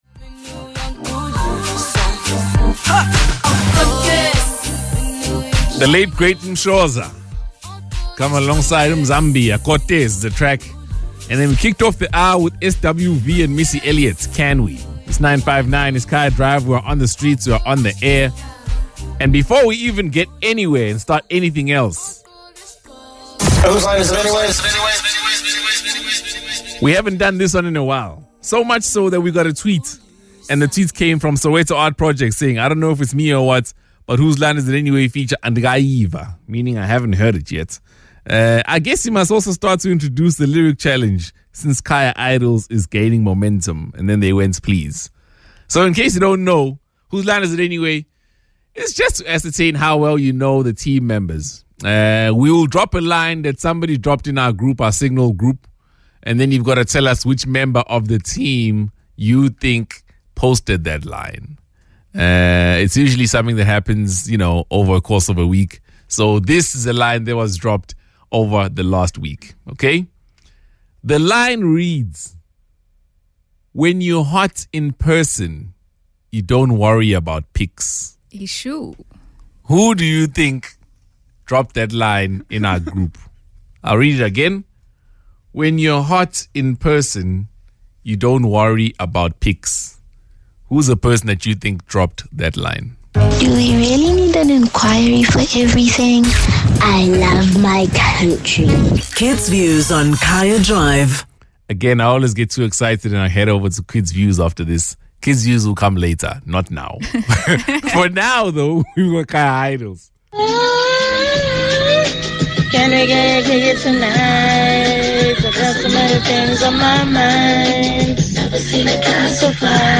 Listen to the team weigh in on the fear of being lonely: